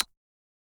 key-press-1.mp3